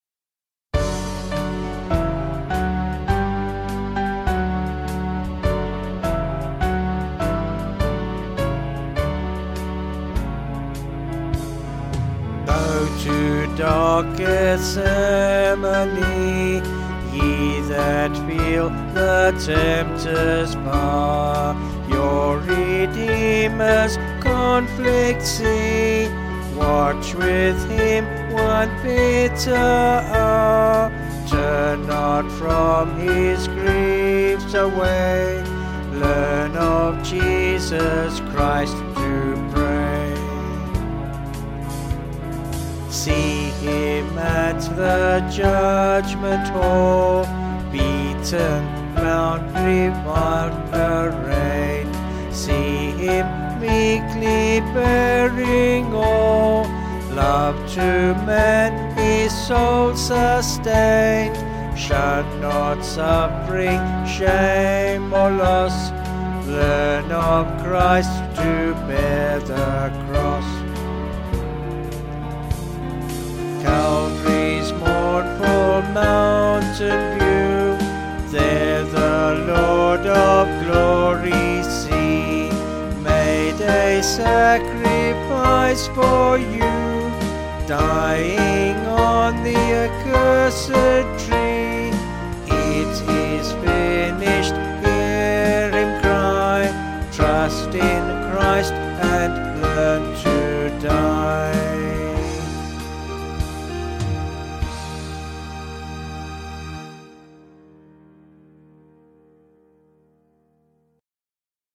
3/D-Eb
Vocals and Band   263.7kb Sung Lyrics